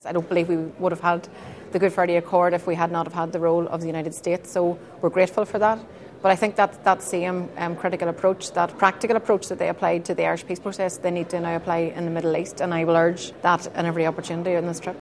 Sinn Féin’s Northern leader thinks the US has a big role to play: